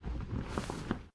catch_air_1.ogg